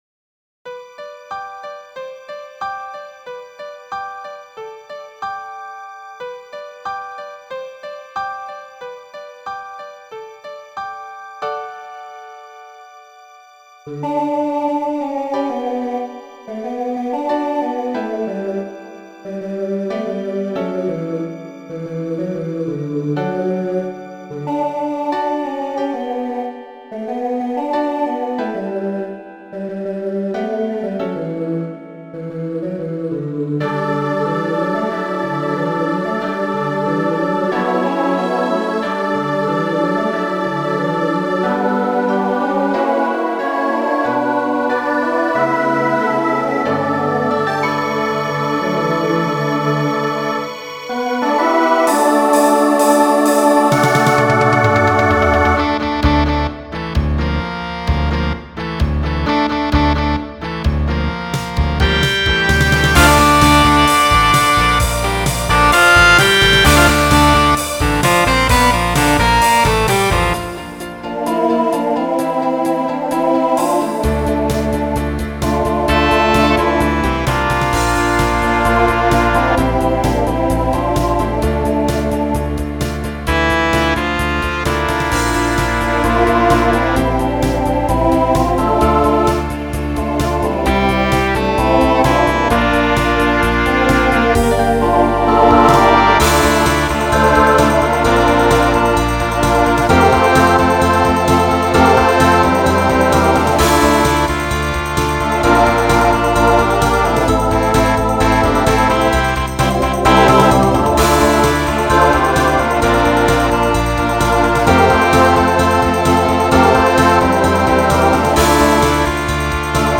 New TTB voicing for 2022.